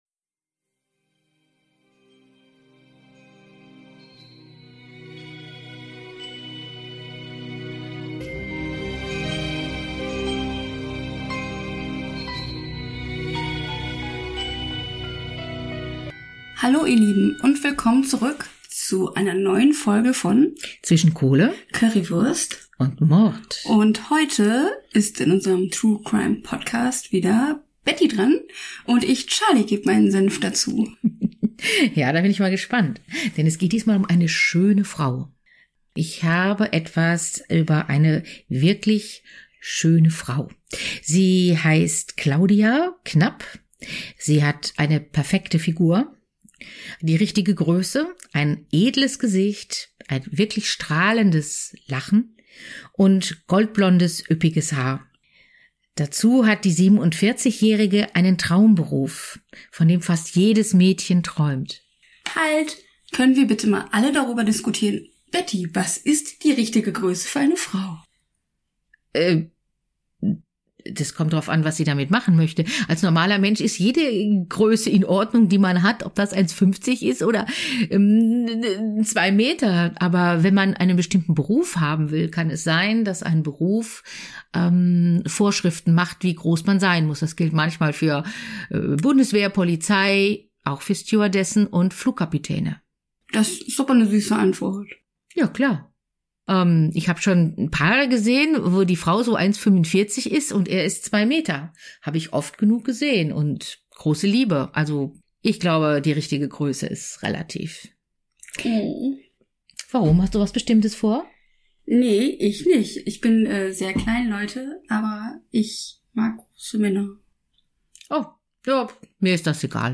Die jeweils andere stellt Fragen und kommentiert.